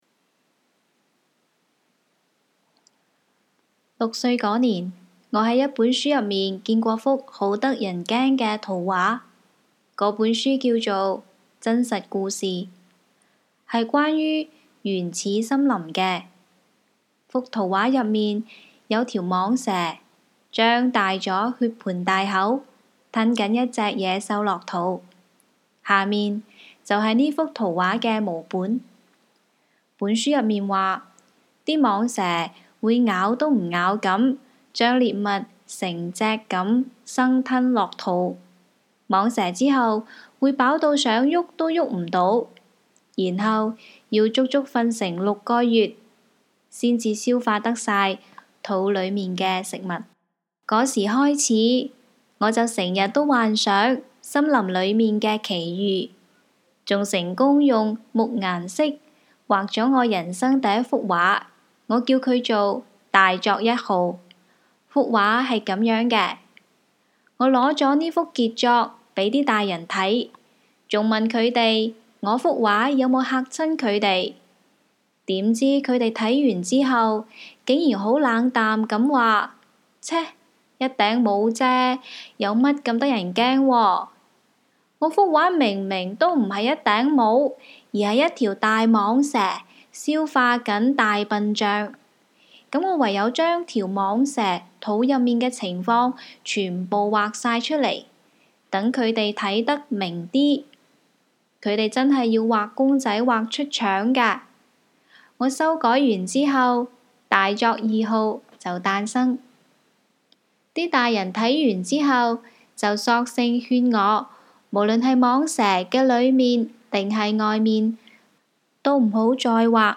Cantonese_-_Guangdong_province_jiangmen_city_.mp3